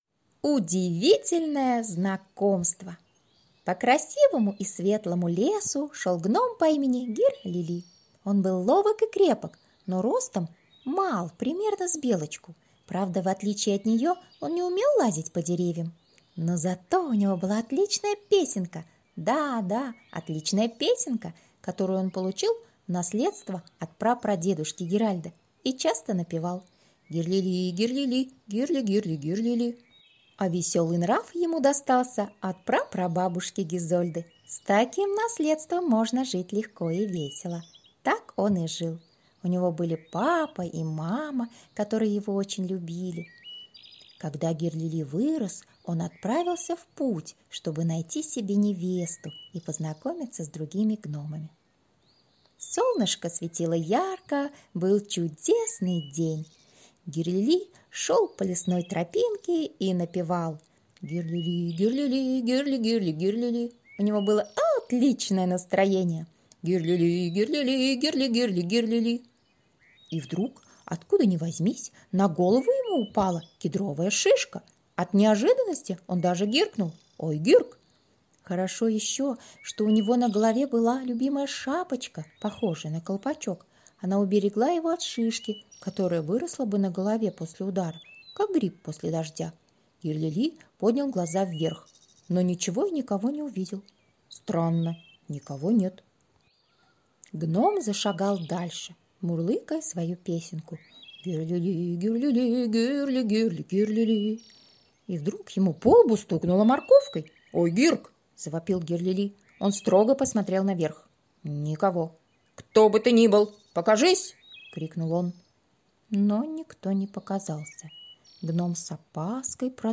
Аудиокнига Гном Гир-Лили и его друзья | Библиотека аудиокниг